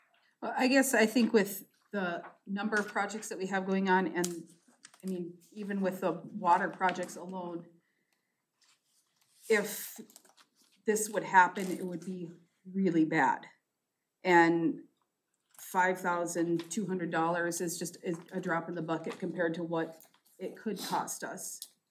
Council member Jade Mound said providing the City protection against hacking scams would be beneficial.